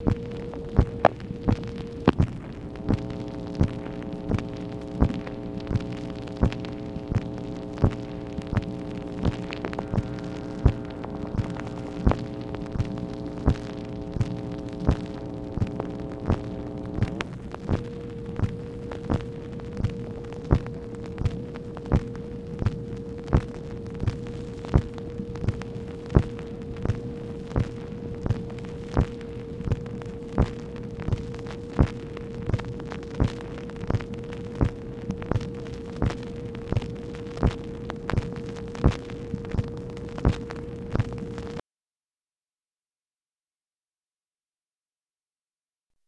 MACHINE NOISE, August 21, 1964
Secret White House Tapes | Lyndon B. Johnson Presidency